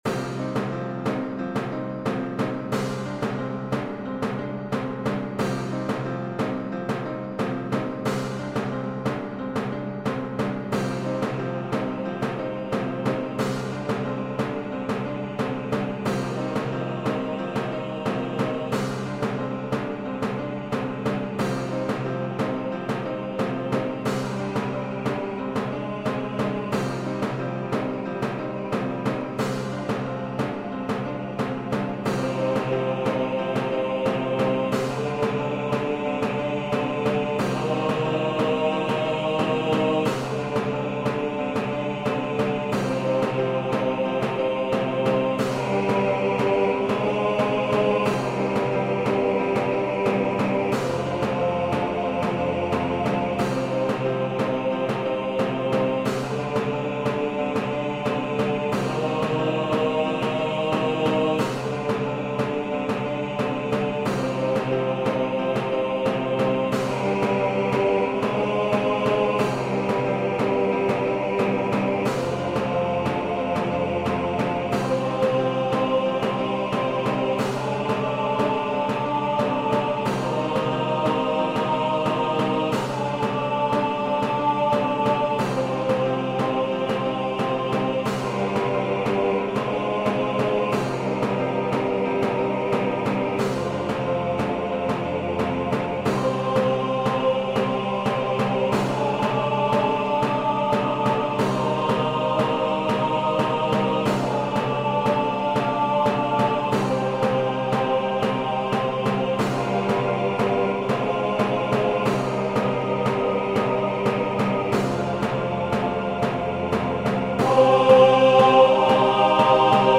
オペラ
MP3練習音源